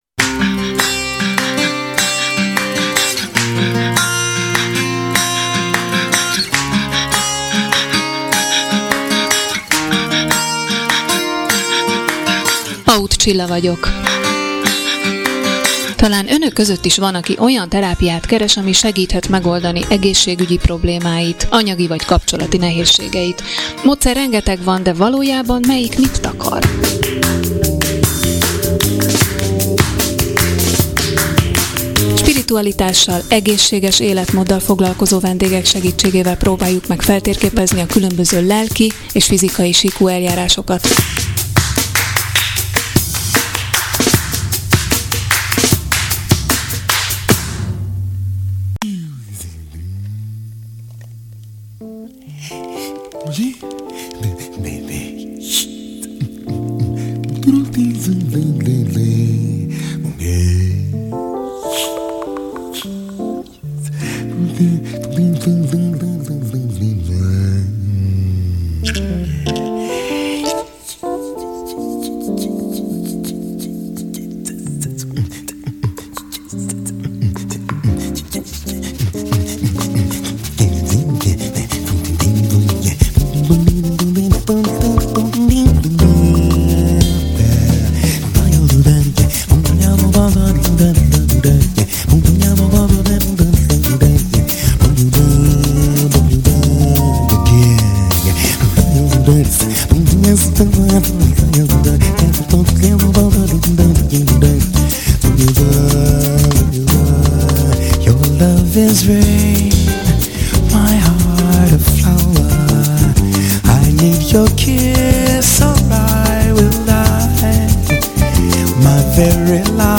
Összefoglaló riport.